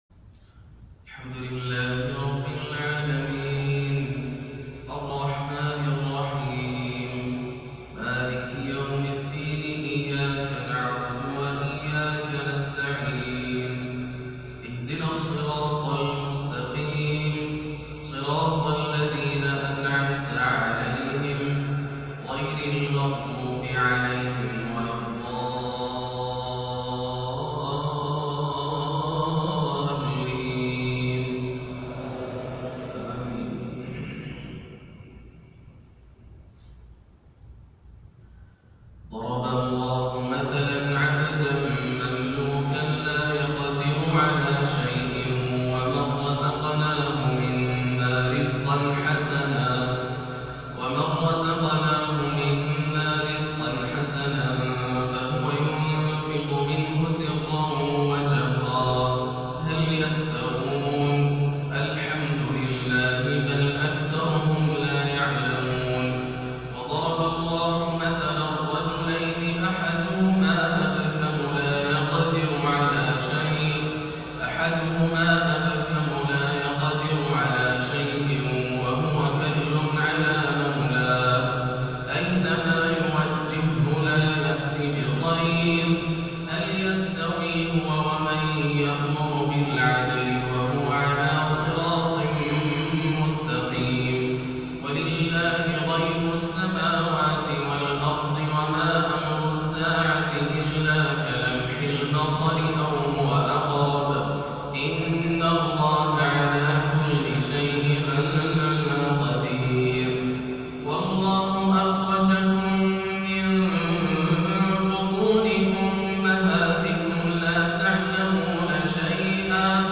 عشاء الاربعاء 7-1-1439هـ من سورة النحل 75-97 > عام 1439 > الفروض - تلاوات ياسر الدوسري